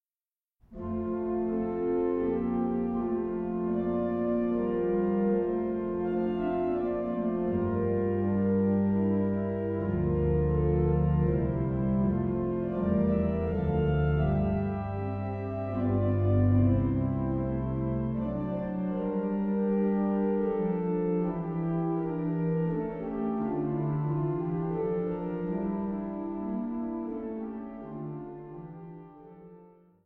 Führer-Skrabl-Orgel in der Evangelischen Kirche Saarlouis